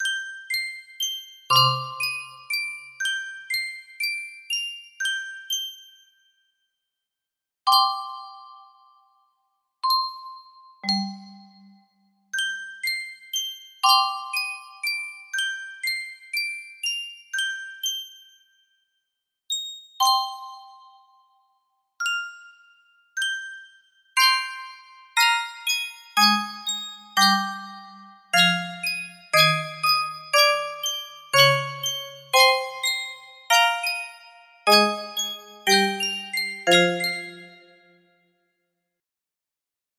Organ music box melody
Full range 60